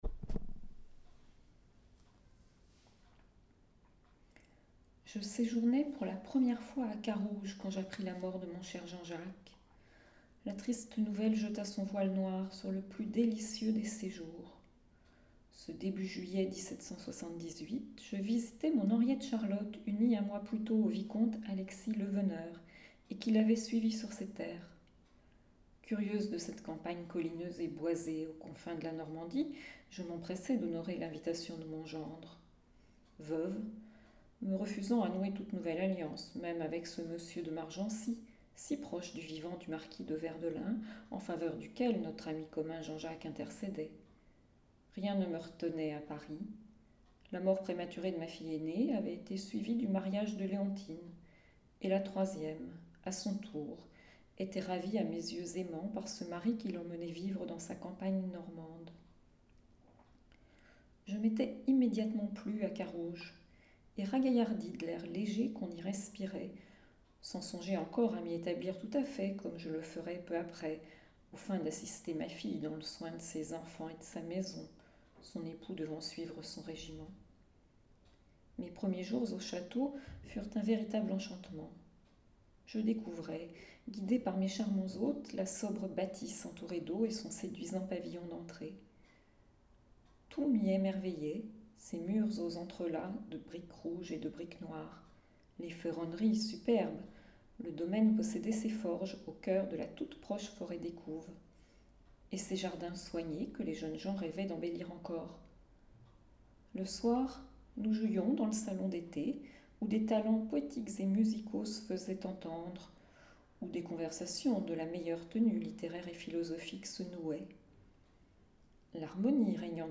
La marquise, grande épistolière comme le XVIIIe siècle en a produit tant, n’ayant pas laissé de mémoires, je lui ai un peu forcé la main, et en résulte une Page arrachée aux mémoires apocryphes de la marquise de Verdelin, datée de juillet 1778. Cliquez ci-dessous si vous souhaitez l’entendre : je vous la lis.